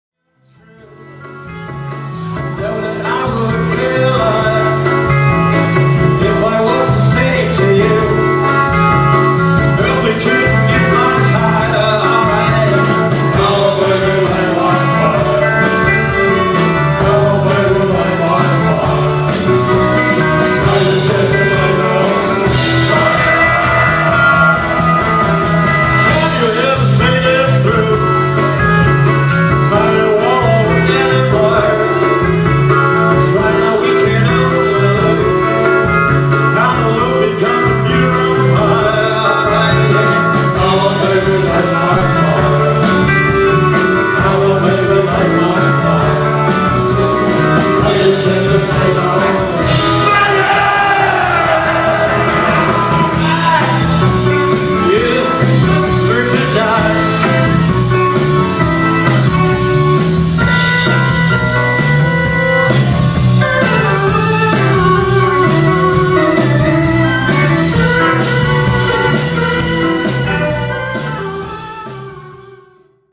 absolutely live mp3-Soundfiles